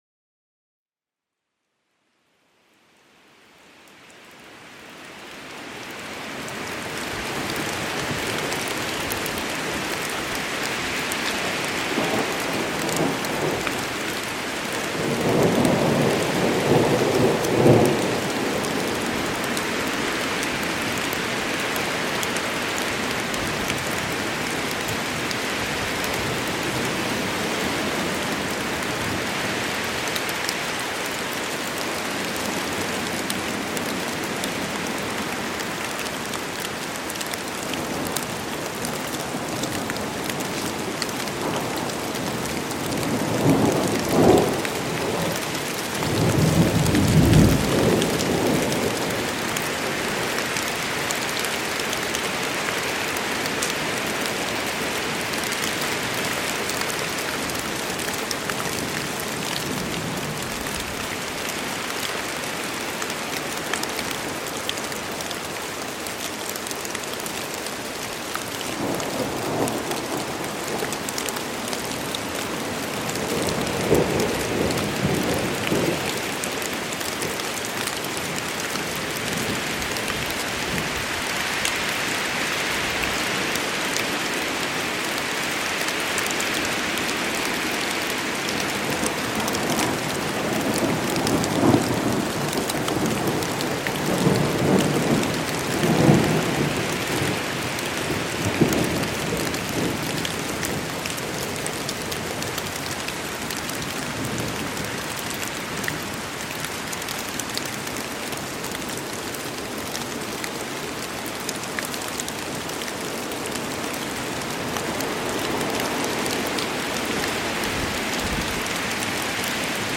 NATURHEILMITTEL: Gewitter-Therapie mit Donner und Regen